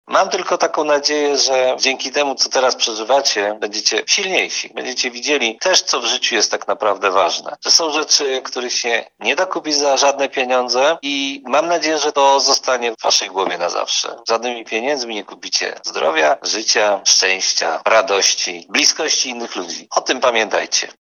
Tarnobrzeg. Do tegorocznych maturzystów zwrócił się były dyrektor LO, wciąż nauczyciel, dziś prezydent miasta.
Do tegorocznych maturzystów zwrócił się nauczyciel, były dyrektor LO imienia Mikołaja Kopernika w Tarnobrzegu, a dziś prezydent miasta.